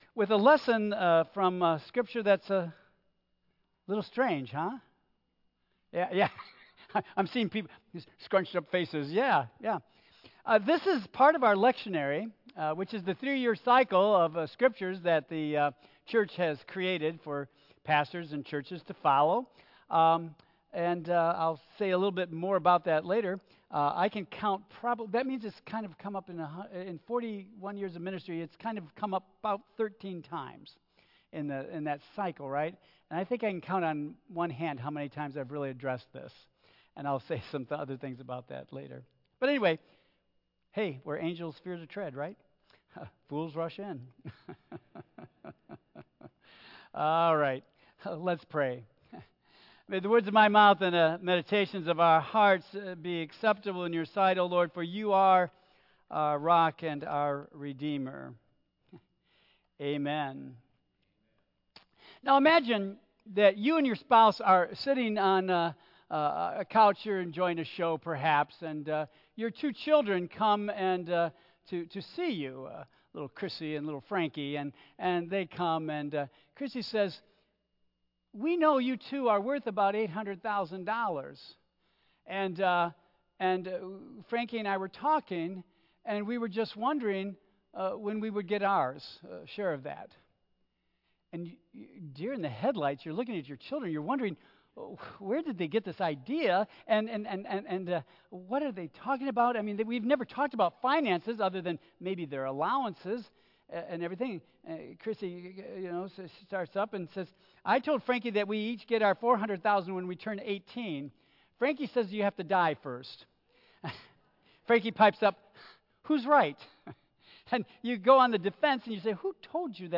Tagged with Michigan , Sermon , Waterford Central United Methodist Church , Worship Audio (MP3) 9 MB Previous A Father-In-Law's Advice Next When Is It "A God Thing"?